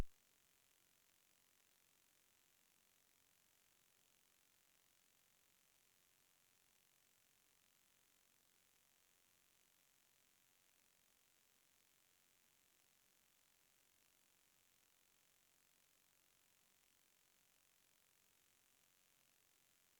ECM 麦克风差分输入连接至 AIC3106 line2+/line2-、然后连接至 AIC3106内具有20dB PGA 的左侧 ADC。
附件是通过 arecord 记录的 wav 文件、如果您放大信号并将每~20ms 看到一次峰值、并且在记录的 wav 文件上有15dB 的数字增益后、噪声会令人厌烦。
AIC3106Noise.wav